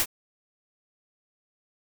pick up.wav